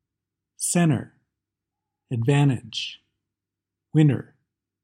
5. Silent t
The letter “t” is often omitted when it comes after “n” and before a vowel (or “r” or “l”).